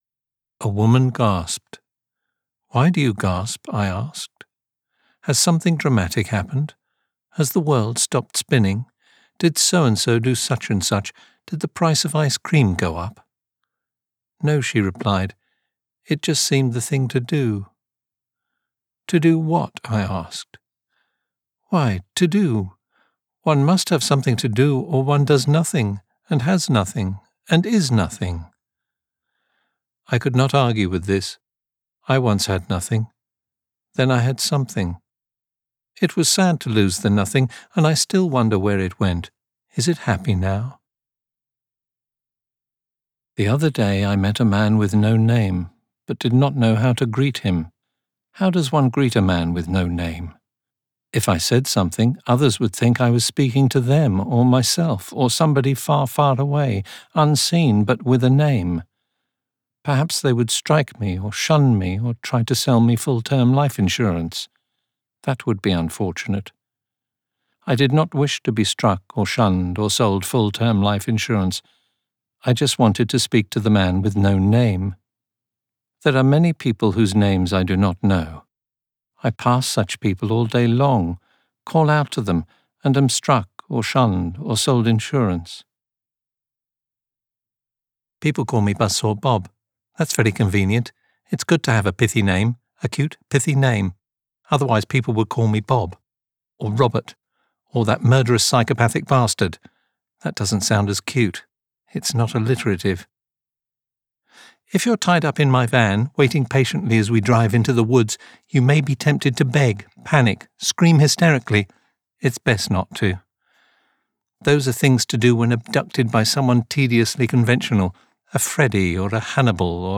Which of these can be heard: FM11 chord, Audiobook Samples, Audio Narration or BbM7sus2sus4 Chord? Audiobook Samples